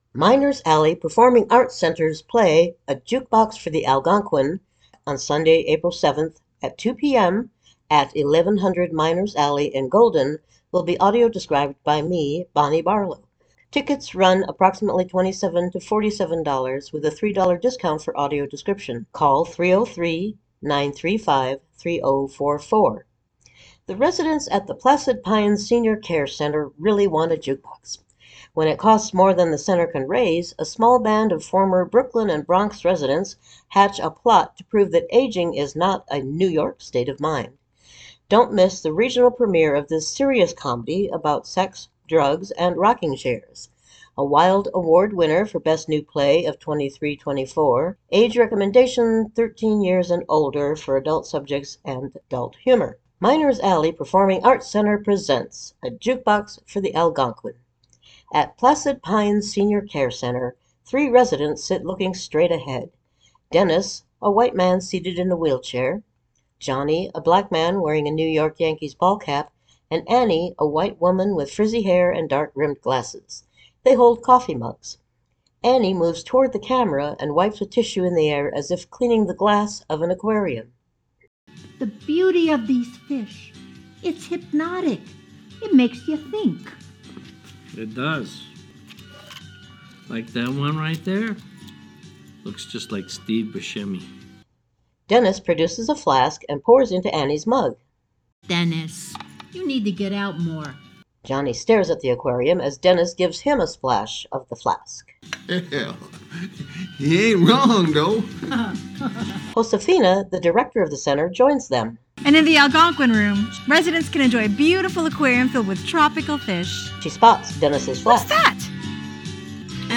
For an audio described promo and preshow description of the show listen to the MP3 audio attached to this e-mail.